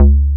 BAS_Jupiter 8  2.wav